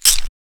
TLC - Gafer (Perc).wav